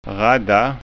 SKÅNSKA UTTAL